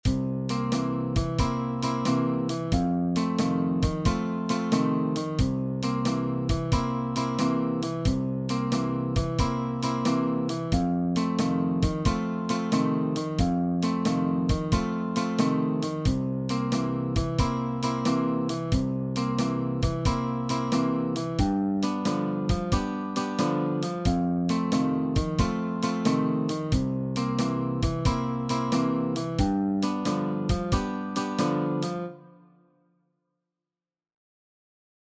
See you soon again ist ein Blues-typischer 12-Takter in C-Dur, den du mit den Fingern auf einer akustischen oder elektrischen Gitarre spielst.
Die Akzente werden durch perkussive Schläge gesetzt.